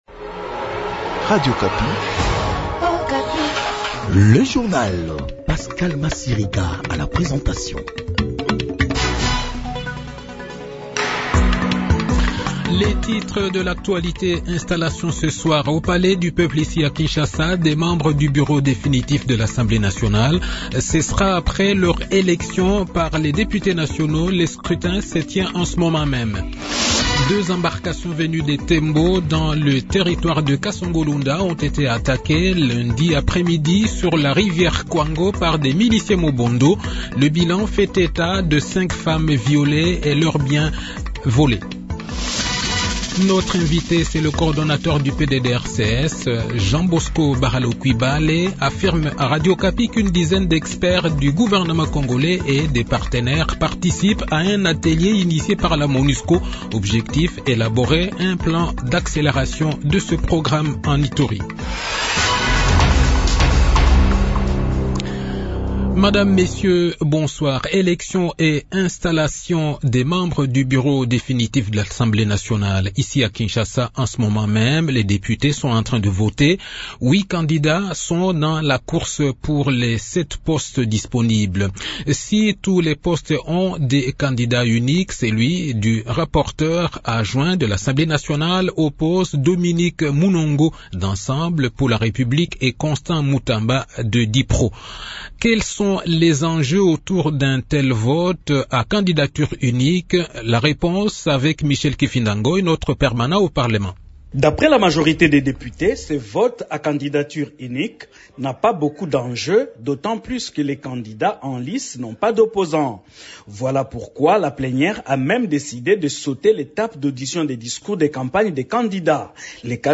Journal Soir
Le journal de 18 h, 22 mai 2024